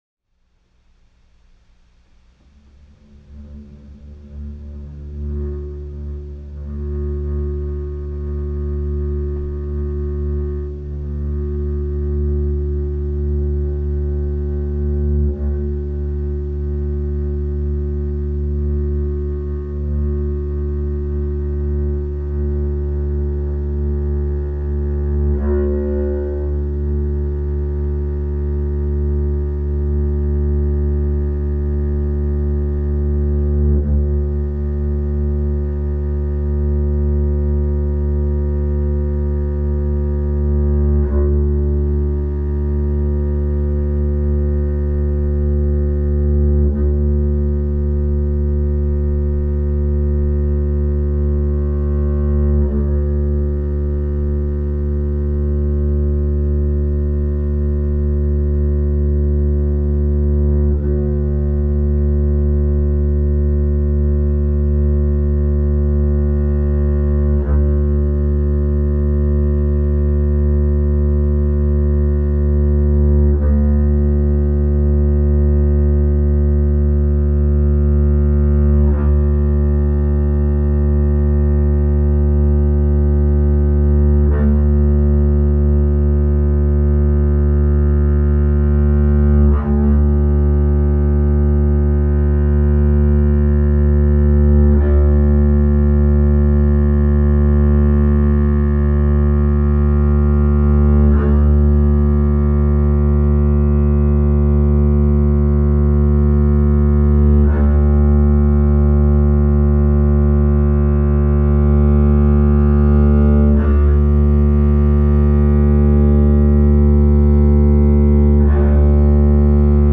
091212, all acoustic
One of the coziest, most intimate sessions ever as far as I can remember, which certainly comes across well from the quietly balanced gestures and tones of this post.